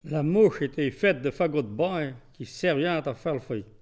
Mémoires et Patrimoines vivants - RaddO est une base de données d'archives iconographiques et sonores.
Enquête Arexcpo en Vendée
Catégorie Locution